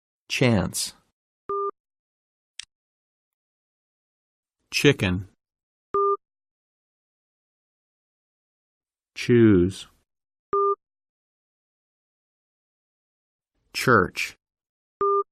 Âm / ch /
• Là âm hơi (voiceless sound) dây thanh âm mở nên khi phát âm, sờ lên cổ không cảm thấy rung.
am-Ch-•-chance.mp3